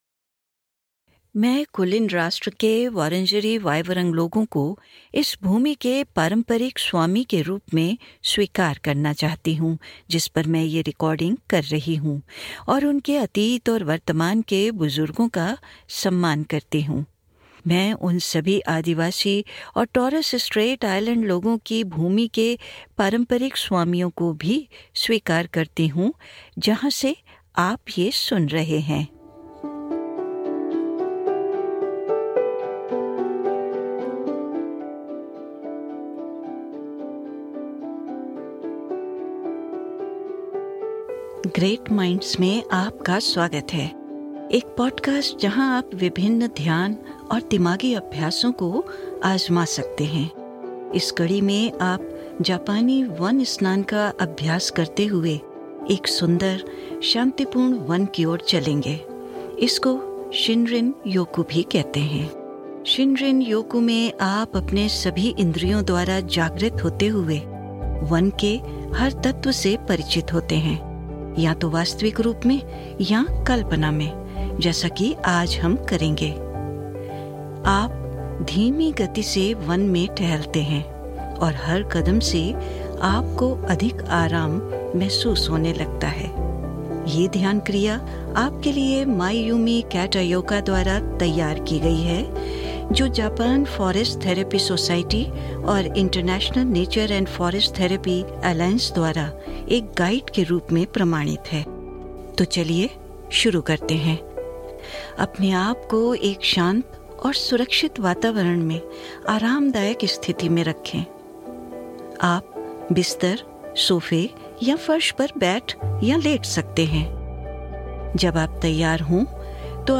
इस ध्यान अभ्यास में, आप कल्पना करेंगें कि आप धीरे धीरे एक सुंदर, शांतिपूर्ण वन में जा रहे हैं। इसके लिये आप एक शांत और सुरक्षित वातावरण में आरामदायक स्थिति में आ जाएं और इस निर्देशित ध्यान अभ्यास को करें।